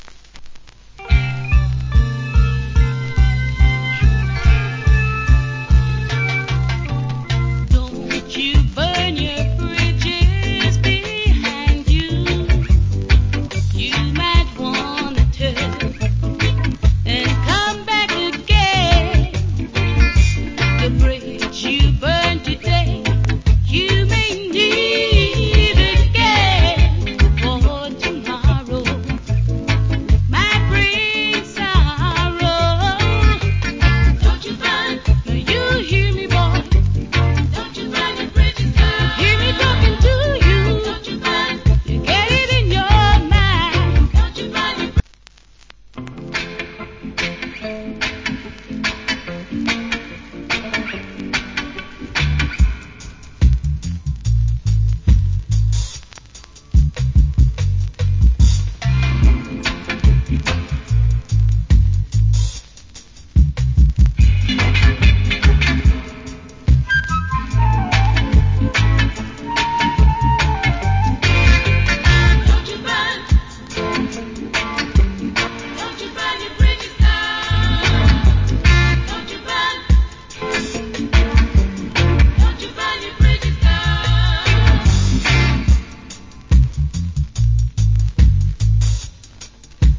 Female UK Reggae Vocal.